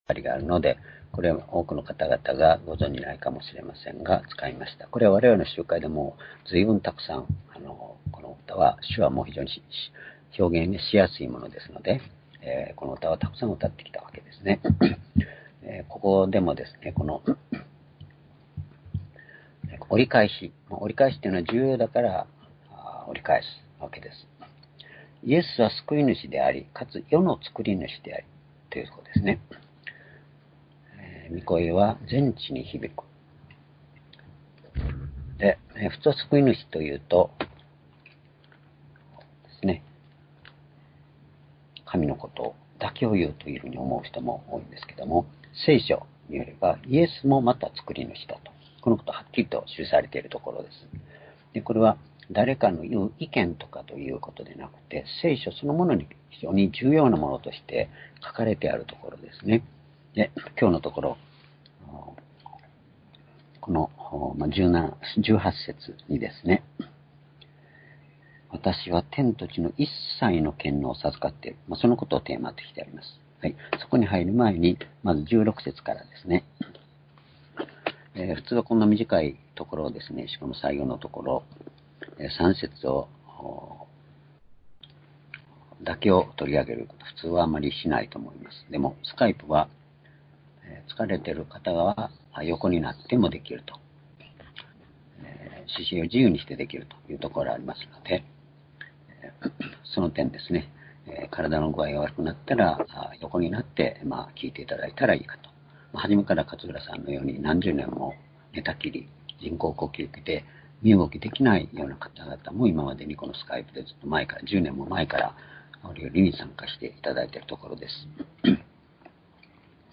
「天と地の権威を持つイエス」-マタイ28章16節～18節-2020年5月24日（主日礼拝）